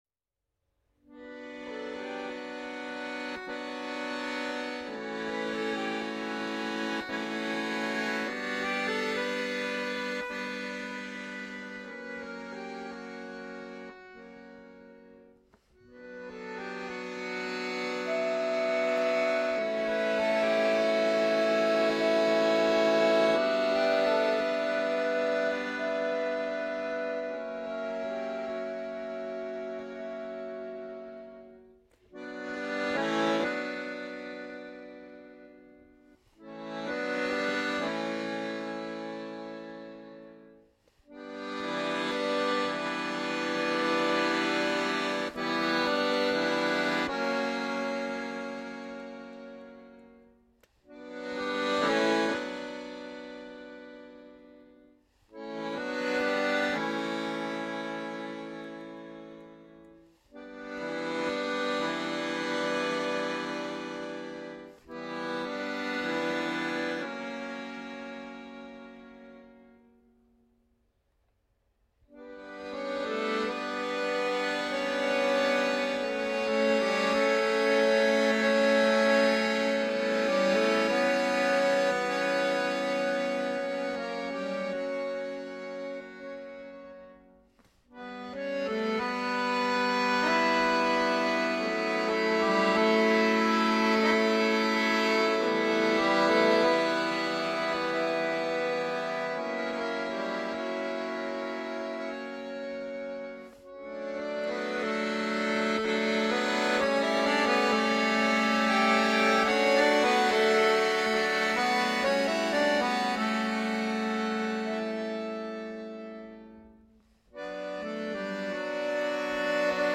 Harmonika